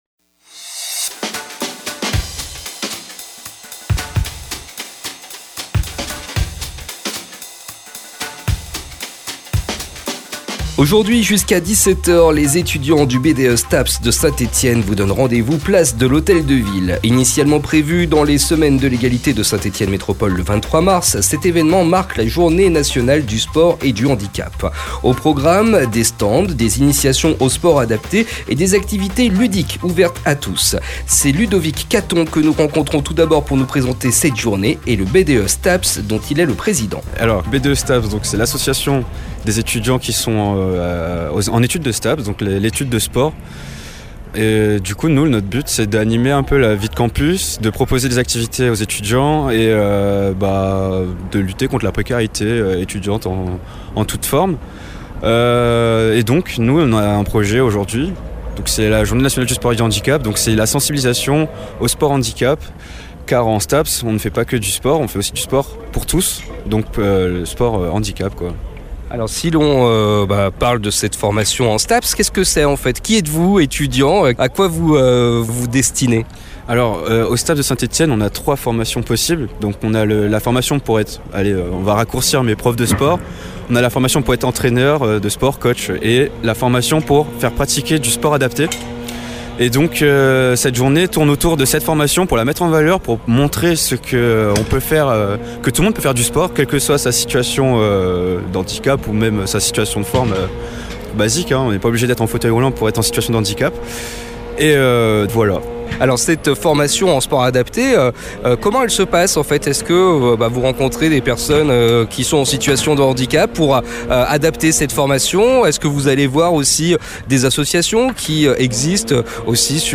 Radio Ondaine, vous propose aujourd’hui, à 11h30 une rencontre